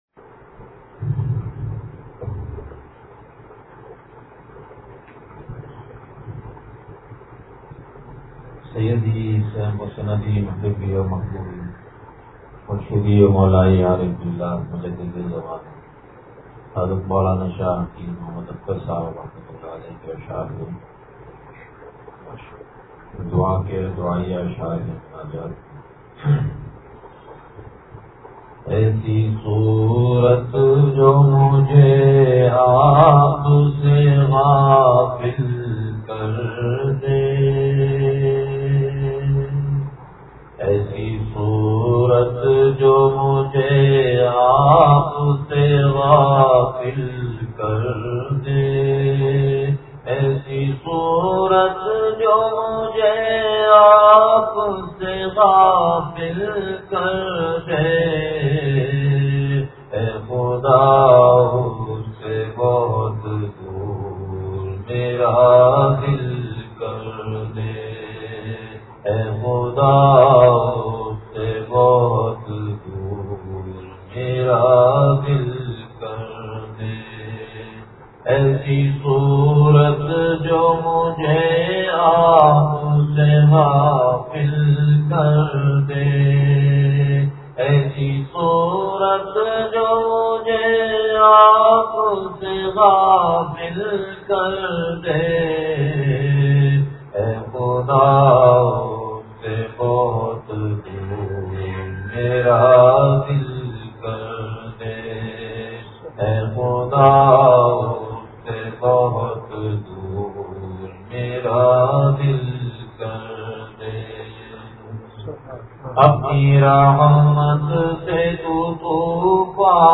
مناجات – ایسی صورت جو مجھے آپ سے غافل کر دے